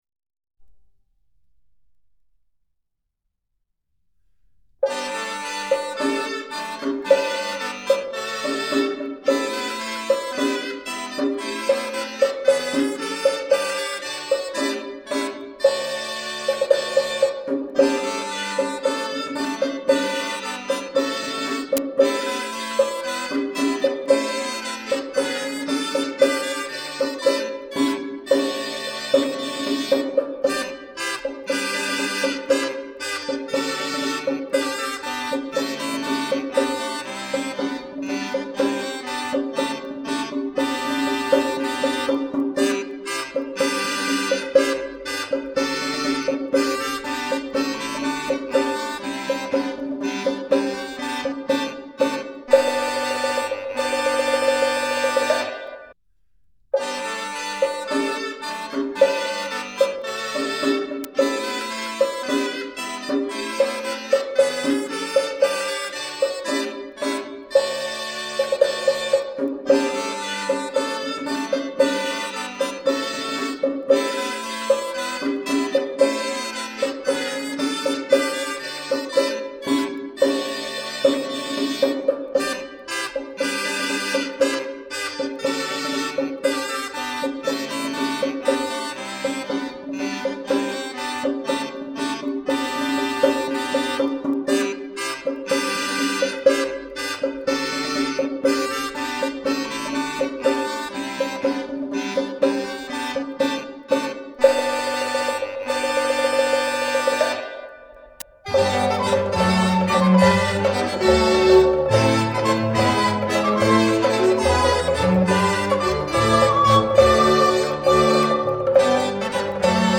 Schließlich wird die Musik schneller und das ist der Beginn des "Nachtanzes".
allemande.mp3